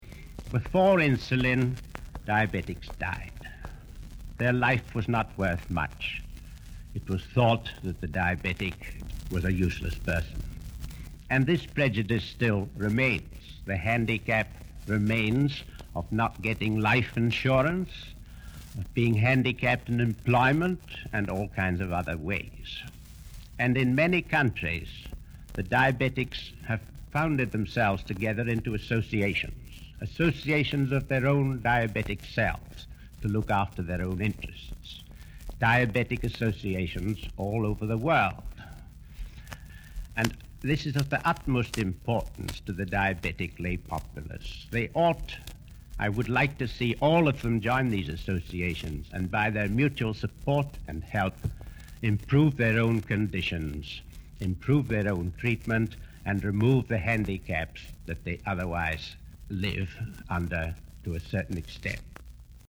This is a recording of a speech made in 1953.